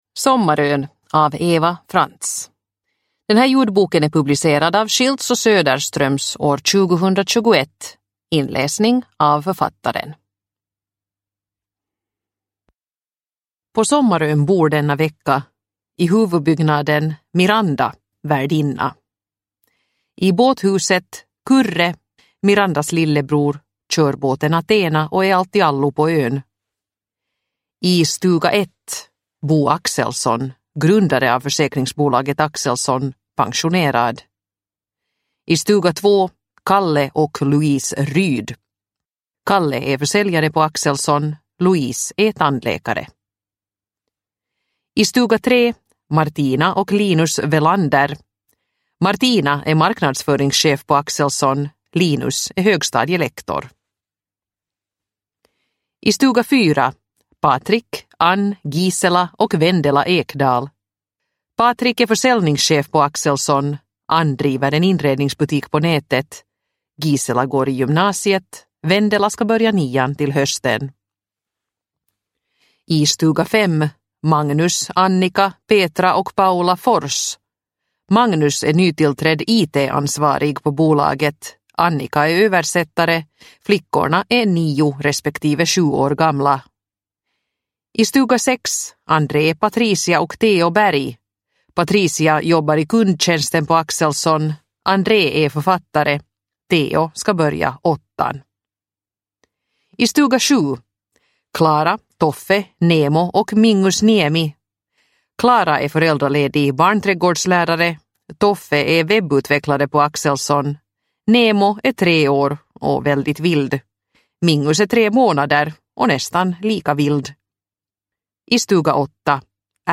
Sommarön – Ljudbok – Laddas ner